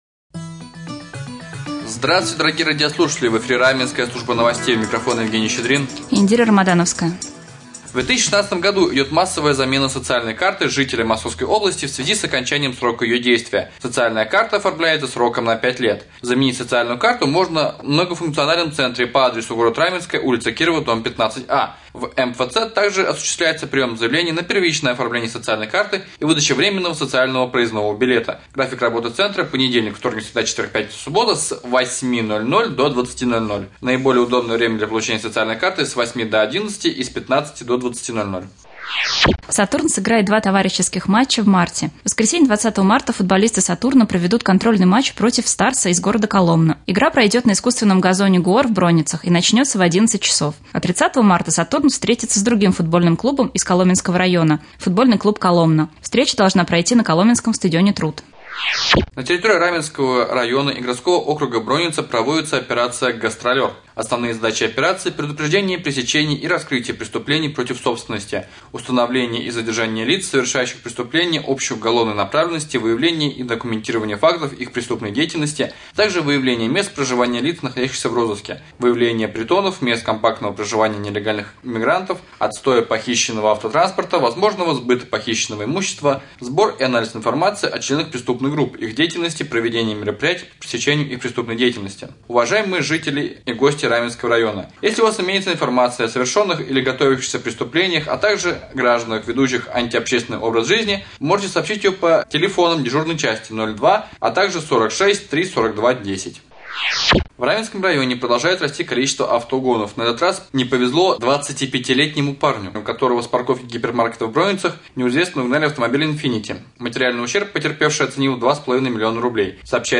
2. Новости